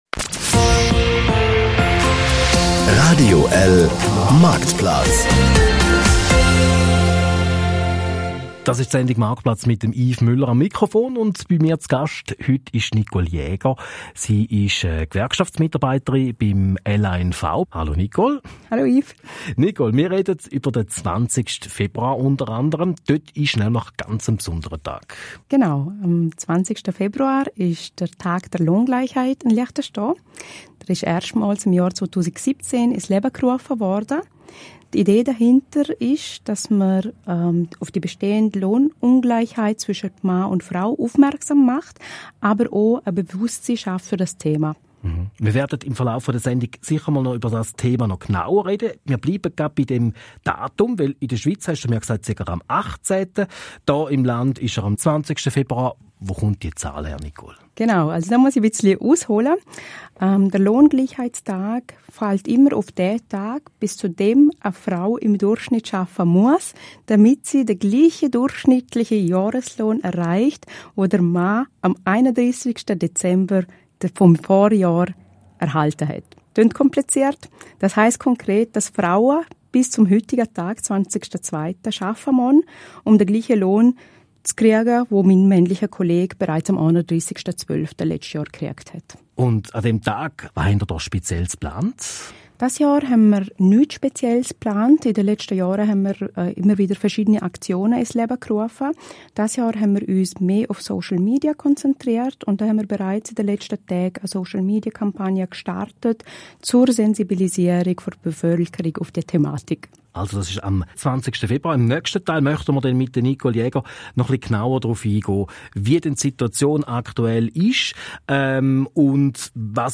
Hier hören Sie den 1. Teil des Radiobeitrages vom 20.02.2023